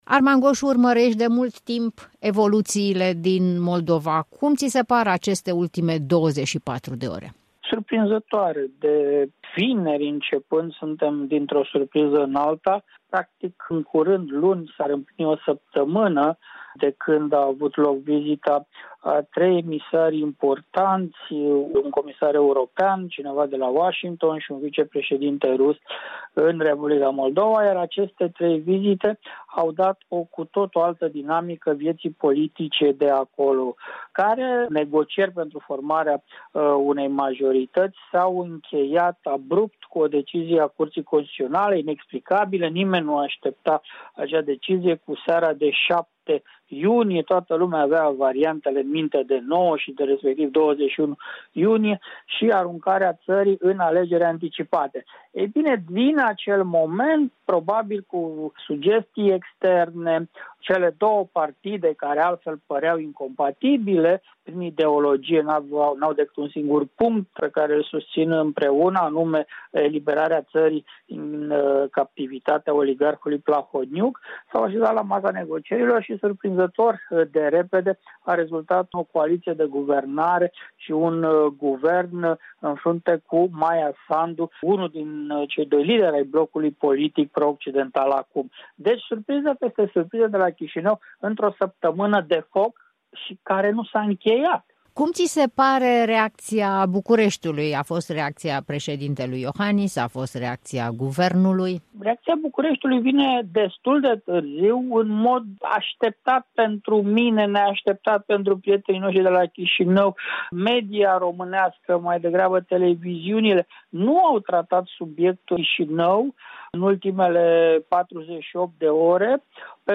în dialog cu comentatorul politic